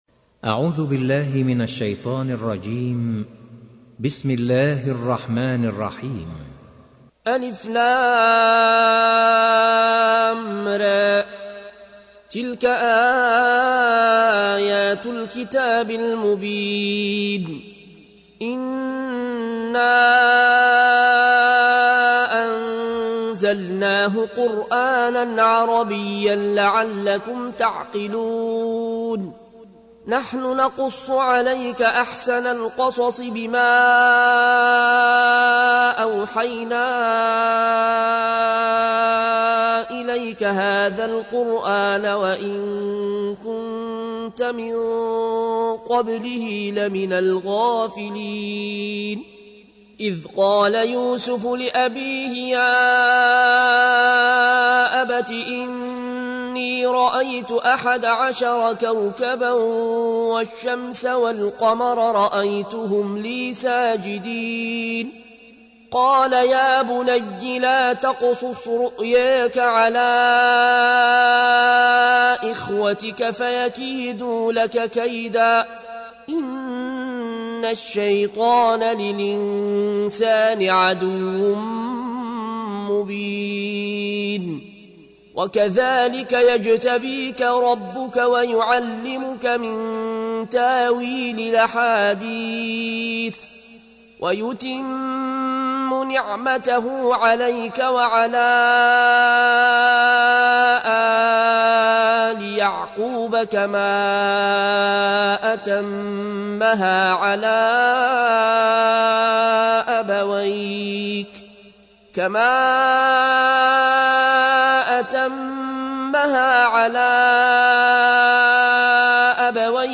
تحميل : 12. سورة يوسف / القارئ العيون الكوشي / القرآن الكريم / موقع يا حسين